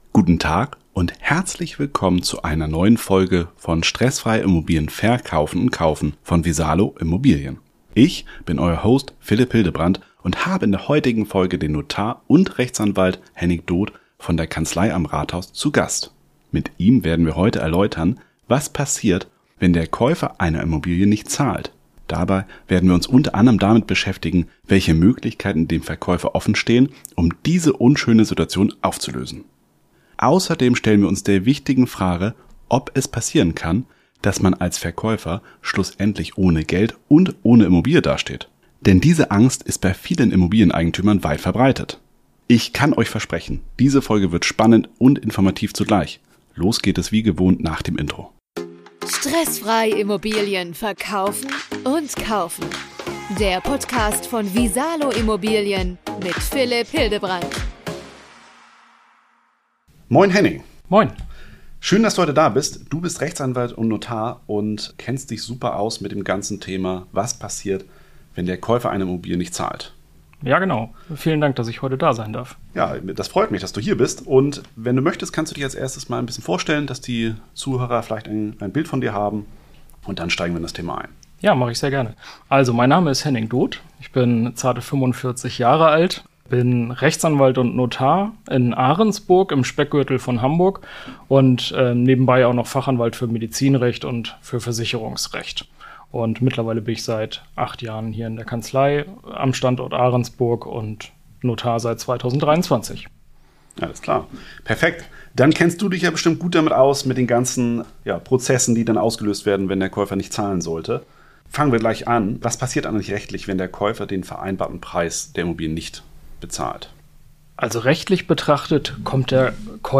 Was tun, wenn der Immobilienkäufer nicht zahlt? - Interview mit einem Notar #113 ~ Stressfrei Immobilien verkaufen & kaufen Podcast